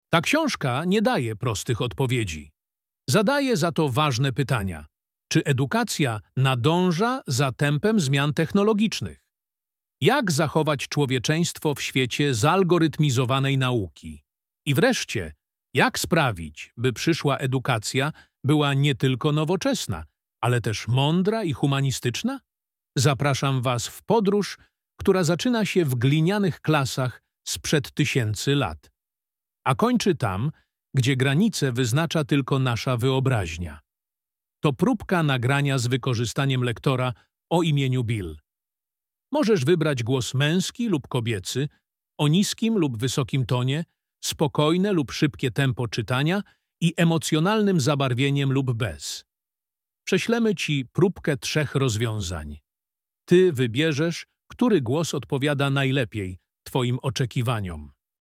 Nagrywanie audiobooków z wykorzystaniem inteligentnego oprogramowania to nowoczesny sposób tworzenia nagrań głosowych, w którym cała narracja powstaje bez udziału tradycyjnego lektora, za to z użyciem sztucznie wygenerowanego głosu o wysokiej jakości.